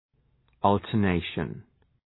Shkrimi fonetik{,ɔ:ltər’neıʃən}